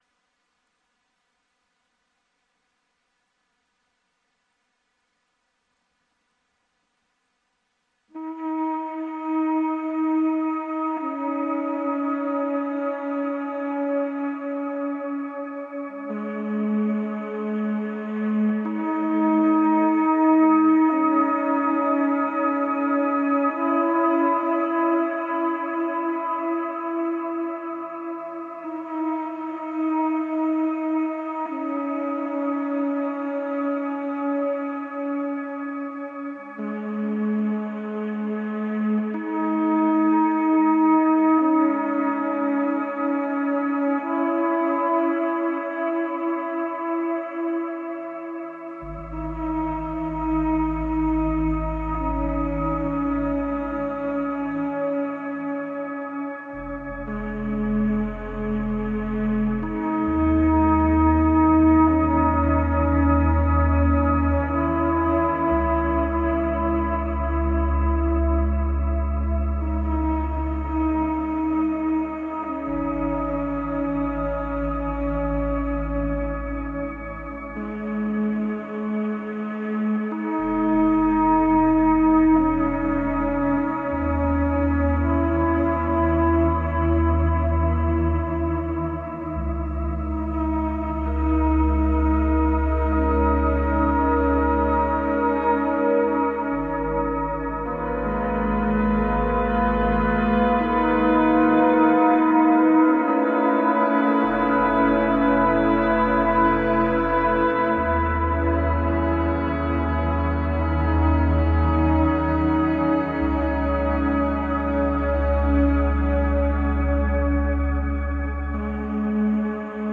Soundscape 08/24/2024 - Electronic - Young Composers Music Forum